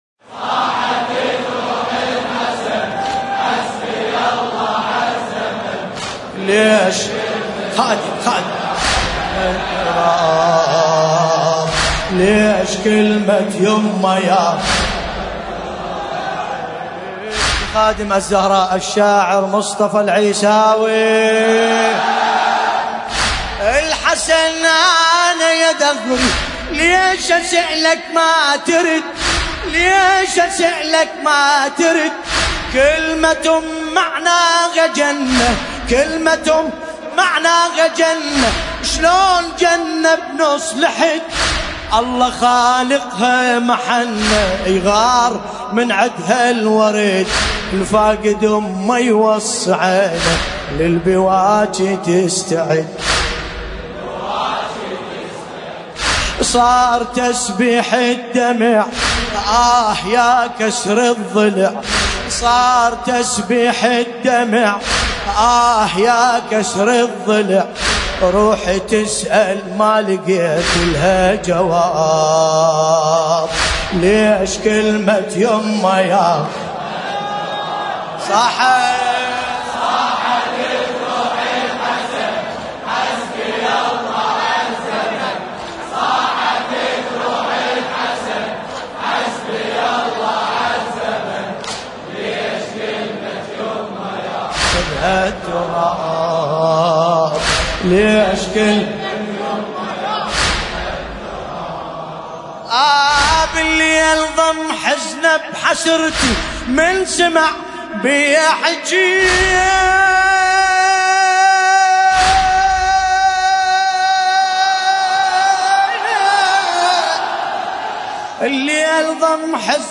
ملف صوتی صاحت جروح الحسن بصوت باسم الكربلائي
الرادود : الحاج ملا باسم الكربلائي المناسبة : الليالي الفاطمية 1440